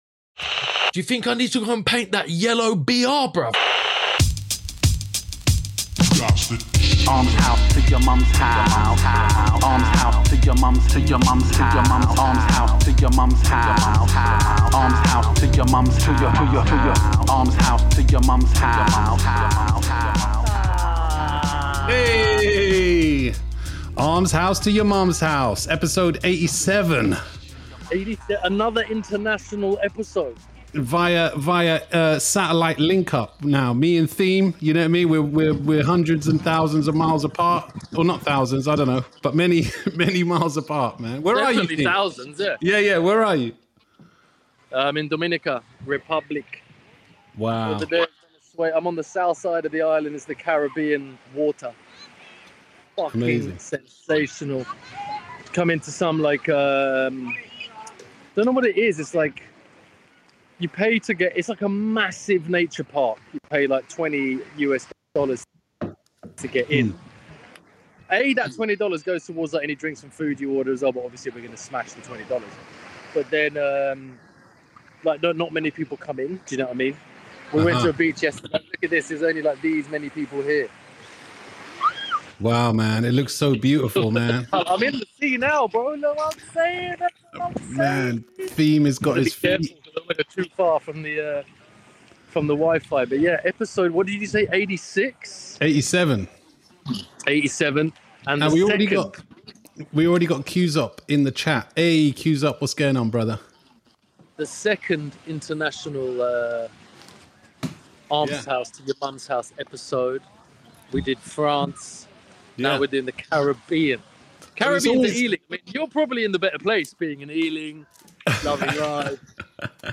We mainly answer listeners questions....